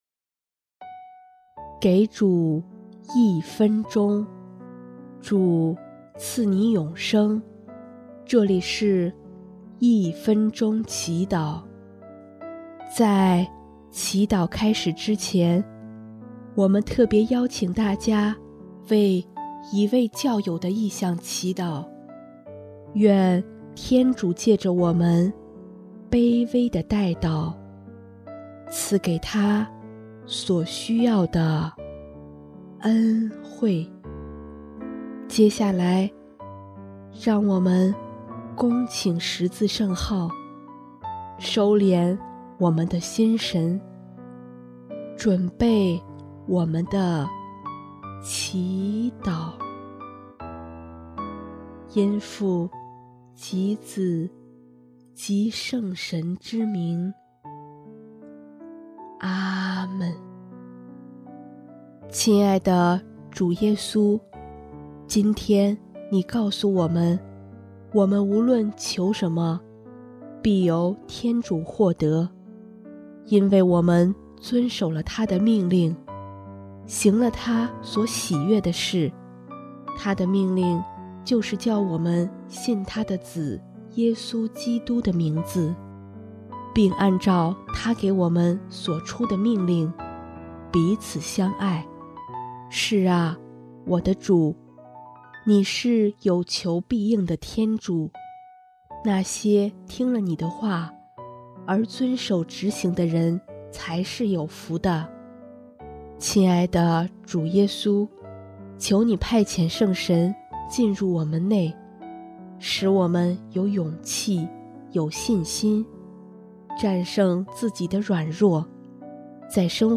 【一分钟祈祷】|1月6日 有福之人
音乐： 第四届华语圣歌大赛参赛歌曲《懂得爱》